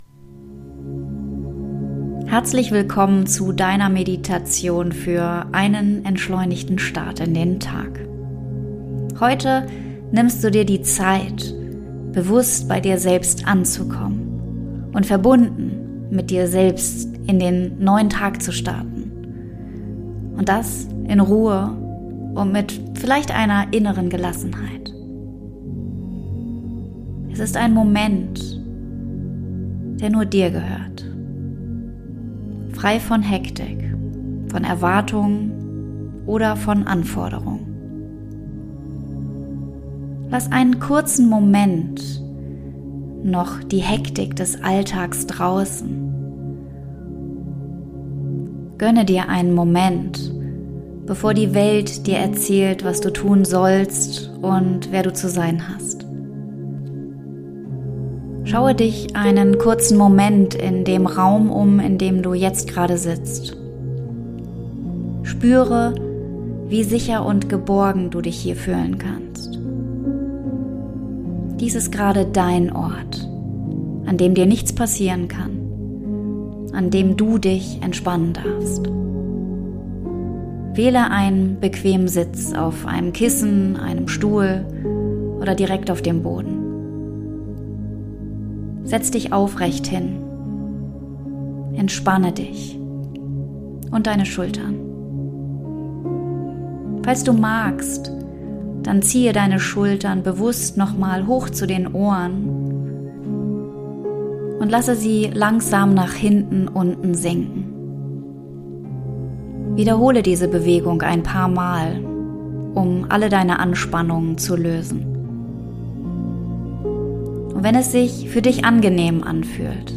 Deine Morgenmeditation für einen perfekten Start